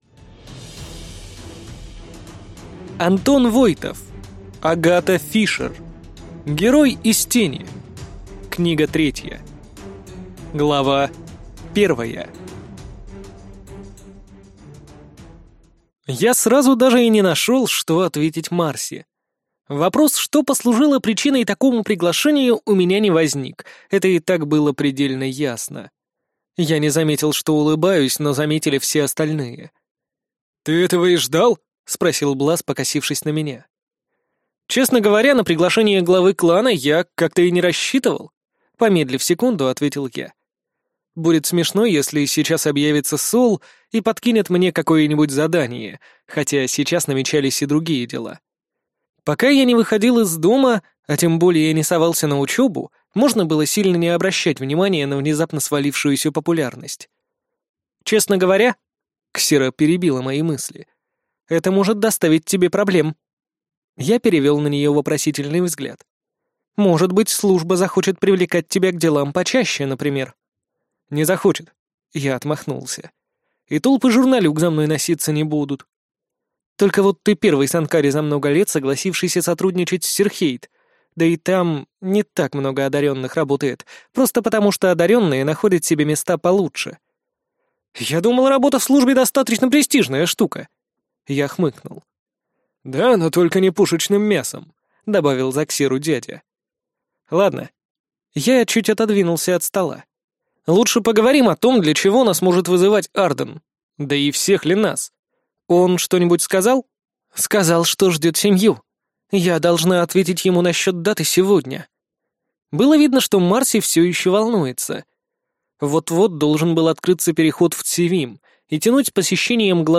Аудиокнига Герой из тени. Книга 3 | Библиотека аудиокниг